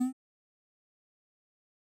coin spawn.wav